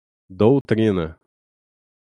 Pronounced as (IPA)
/do(w)ˈtɾĩ.nɐ/